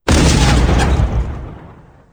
DroneGets.wav